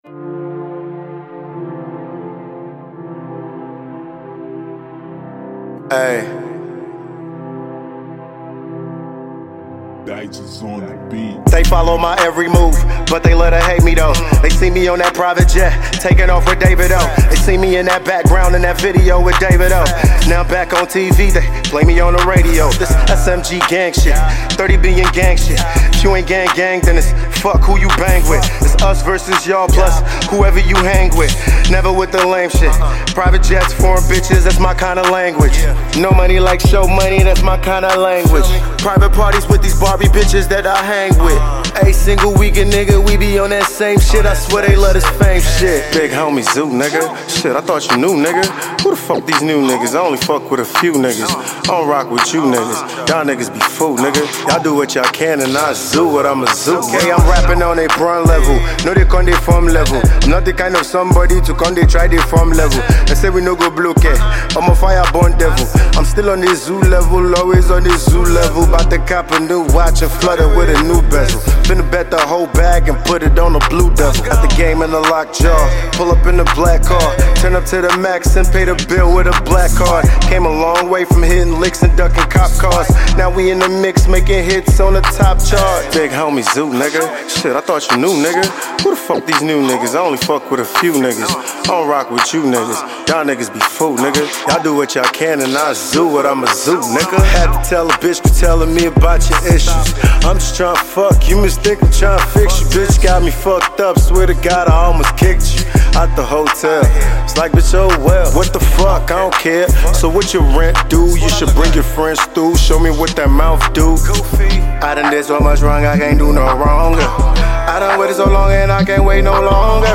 Nigerian veteran rapper